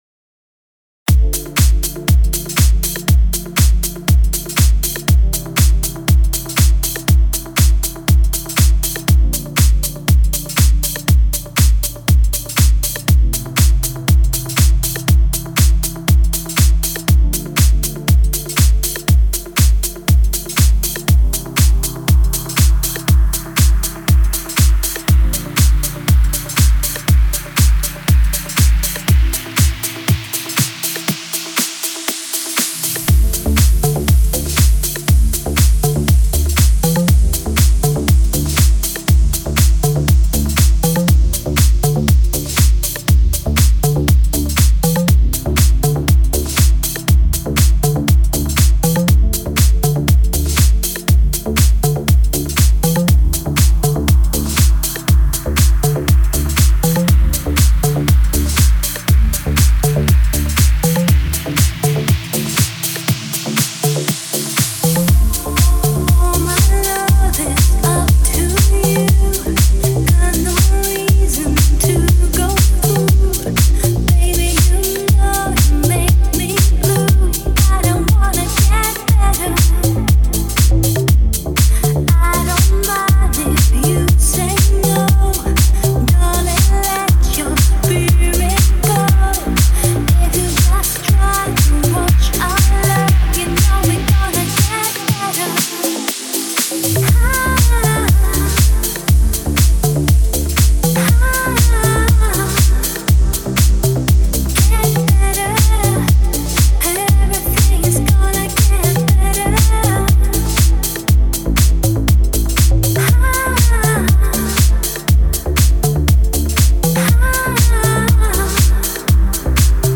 Назад в Deep House
Стиль: Deep House